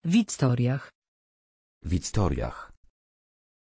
Pronunciation of Victoriah
pronunciation-victoriah-pl.mp3